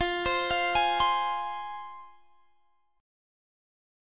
Fm Chord
Listen to Fm strummed